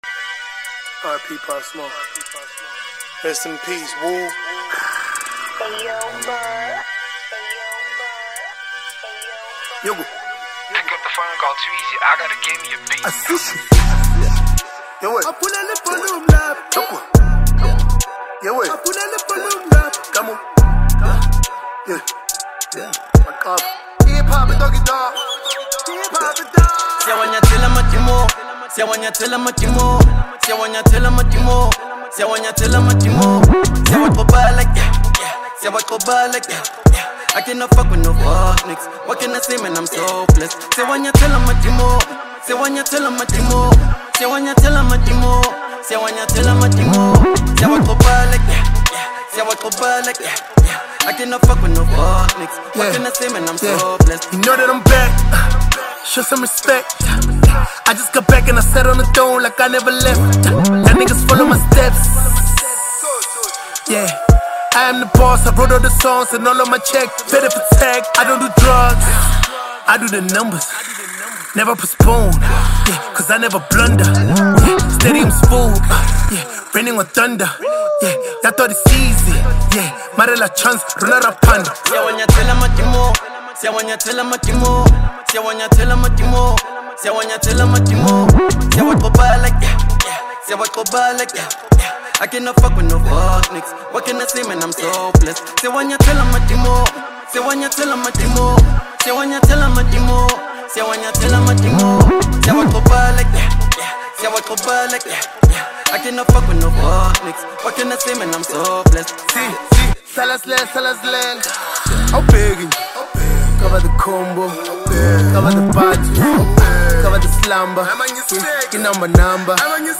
Legendary SA rapper